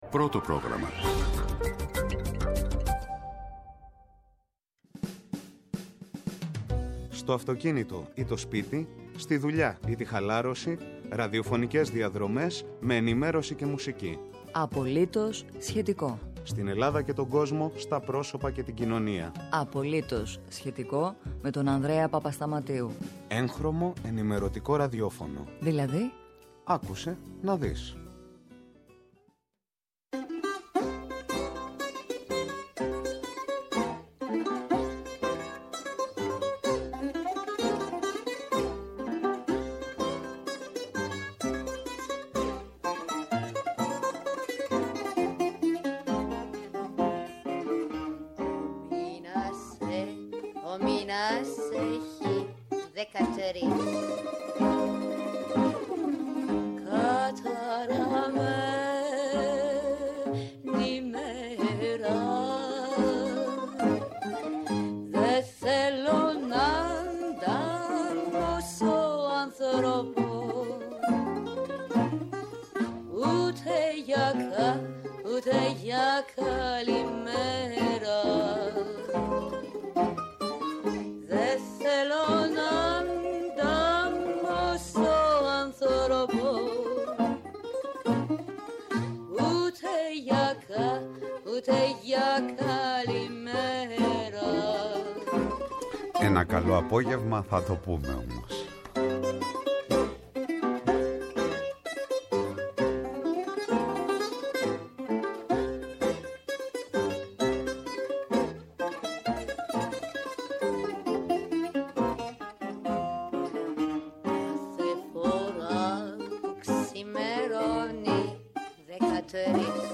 Αυτοκίνητο ή σπίτι, δουλειά ή χαλάρωση, ραδιοφωνικές διαδρομές σε Ελλάδα και Κόσμο, σε Πρόσωπα και Κοινωνία. Αφού επικαιρότητα δεν είναι μόνο το «πρώτο θέμα» και η ενημέρωση δεν χρειάζεται να σβήνει το χαμόγελο, αφού το καλό τραγούδι δεν είναι ποτέ ντεμοντέ κι άποψη δεν σημαίνει να στοιχίζεσαι με τη «μία» ή την «άλλη» πλευρά, ακούστε έγχρωμο ραδιόφωνο* και συντονιστείτε…